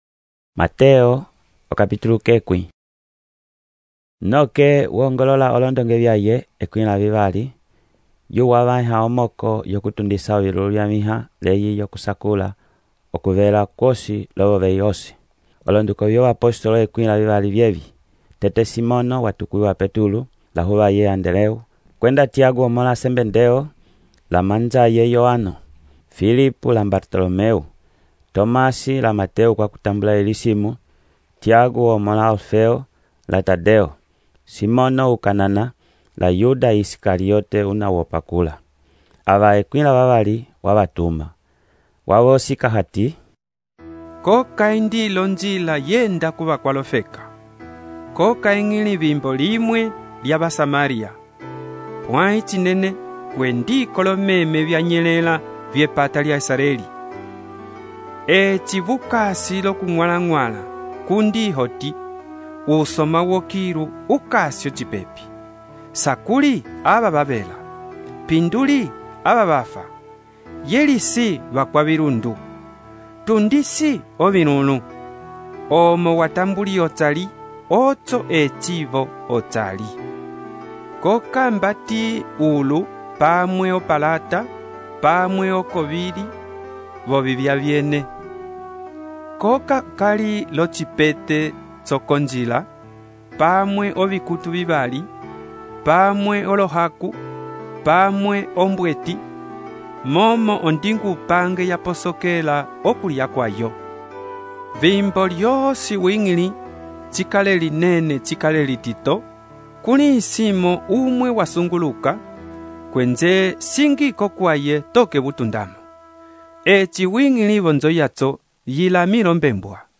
texto e narração , Mateus, capítulo 10